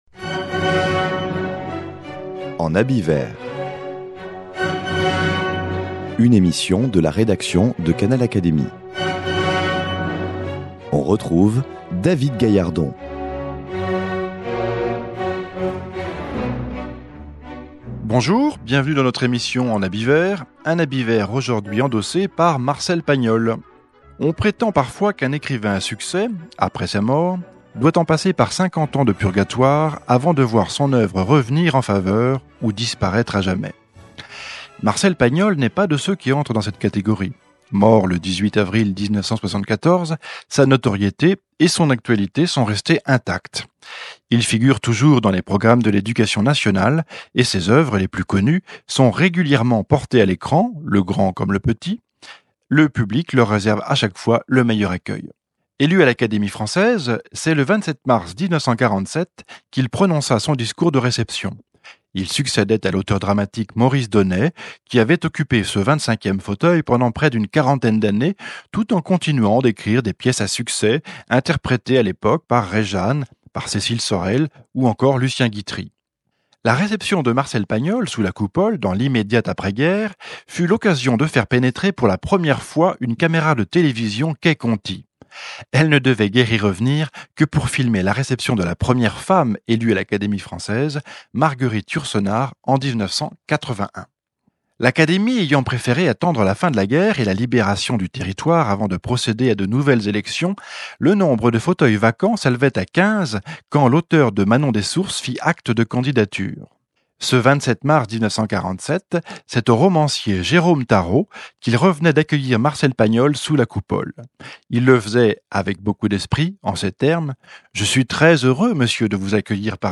en reprenant notamment les discours prononcés lors de sa réception académique.